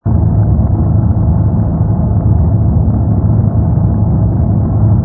rumble_pi_h_fighter.wav